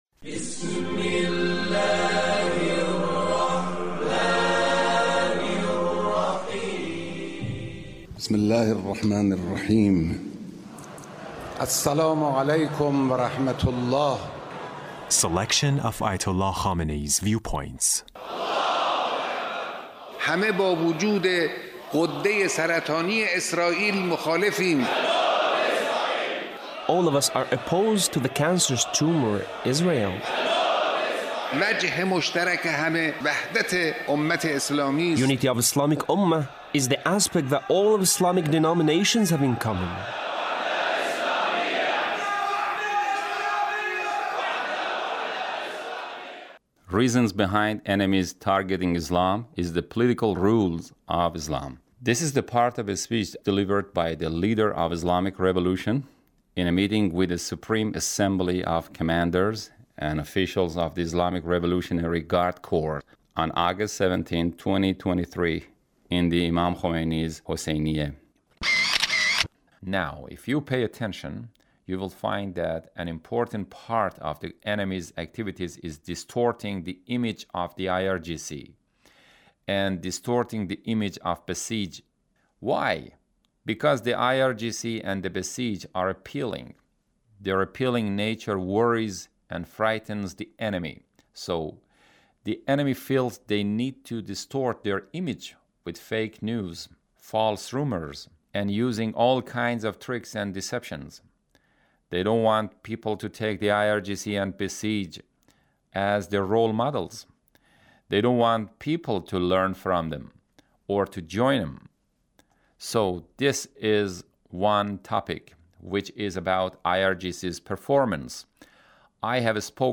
Leader's Speech (1814)